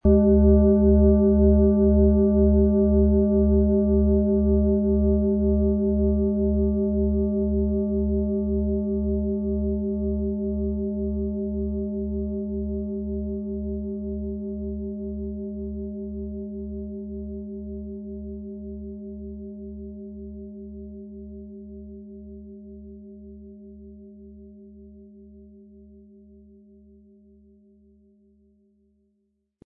Diese tibetische Klangschale mit dem Ton von Saturn wurde von Hand gearbeitet.
Sanftes Anspielen mit dem gratis Klöppel zaubert aus Ihrer Schale berührende Klänge.
MaterialBronze